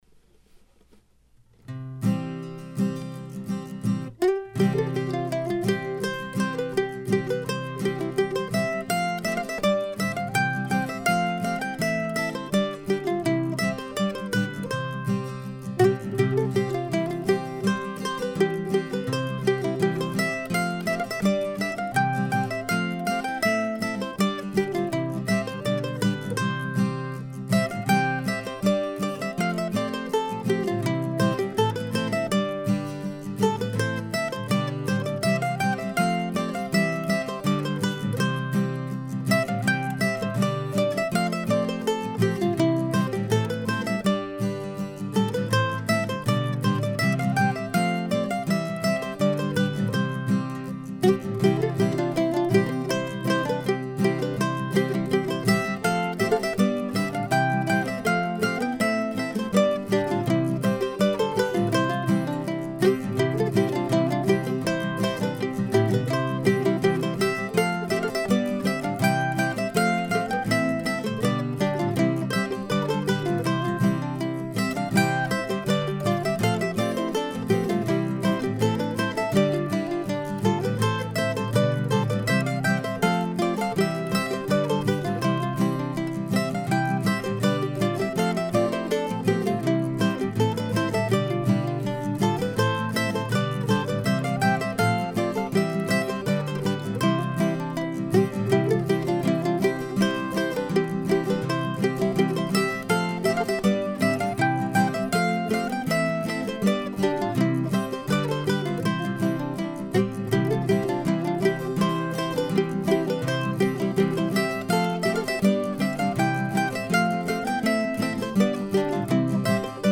As often happens after an encounter with great Scandinavian musicians I was inspired to write something vaguely in the same style.